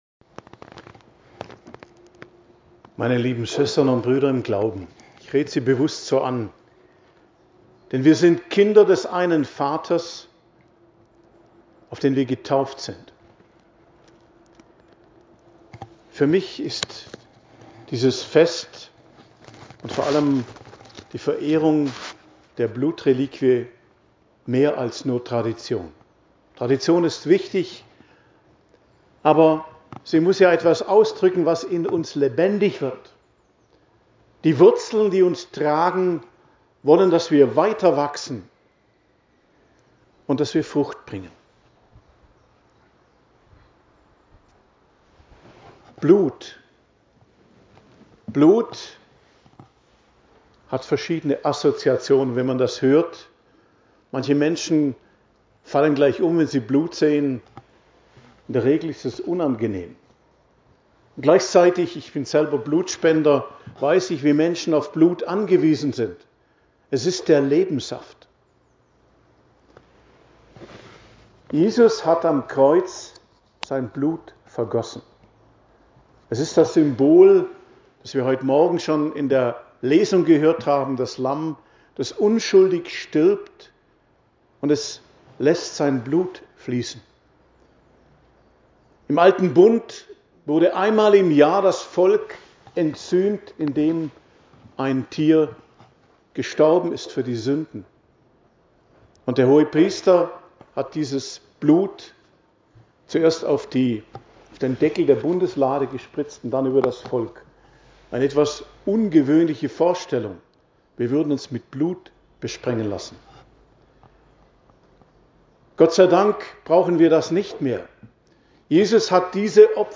Festpredigt am Blutfreitag auf dem Gottesberg Bad Wurzach ~ Geistliches Zentrum Kloster Heiligkreuztal Podcast